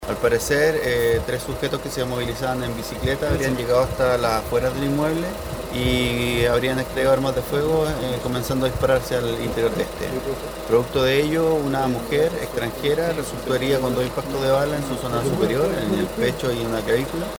El fiscal ECOH, Luis Isla, comentó que los presuntos autores de los disparos serían tres personas que se movilizaban en bicicleta.